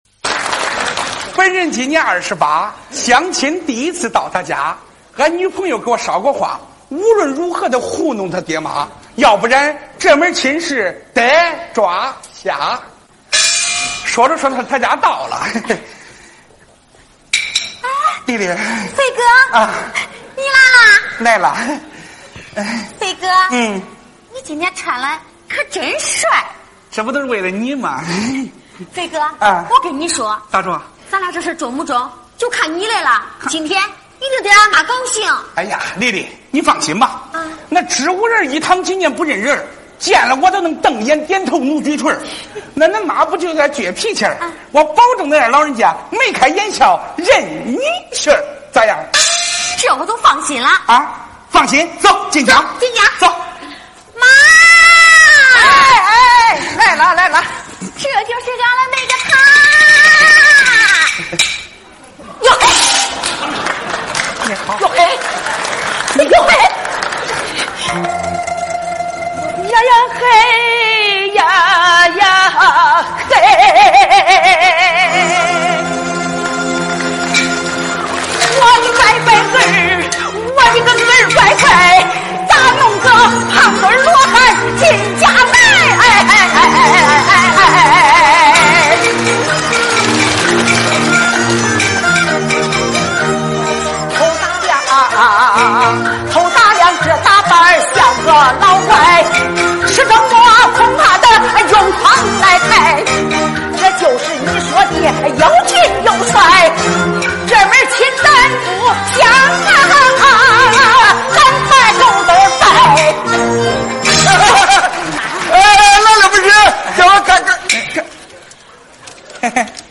范军小品《胖女婿到丈母娘家》_东方“织”乐